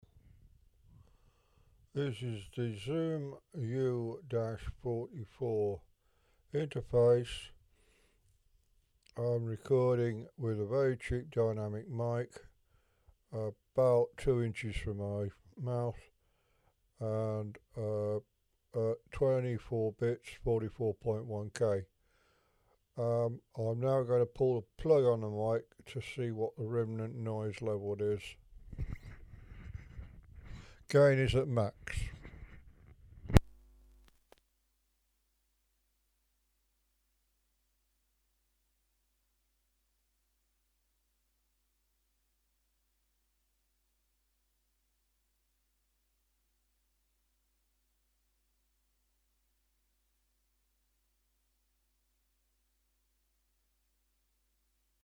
That's a bit o me recorded on a Zoom U-44 I bought because they were a good price from CPC. about £70 with shipping.
It is both an optical and co-ax S/PDIF A/D. D/A. The mic used in the clip was a dirt cheap G158MB dynamic and needed all the 44's gain but it still returned a respectable -69dBFS noise floor.